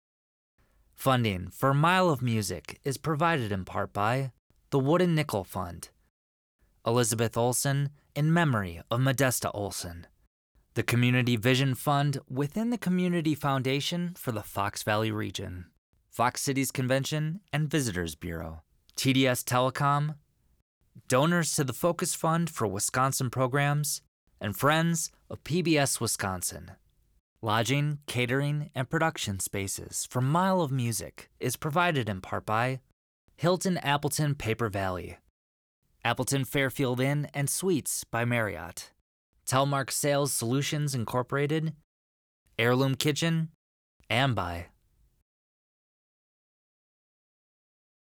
FUNDERS_V3_DOCEXPORT_DIALOG.wav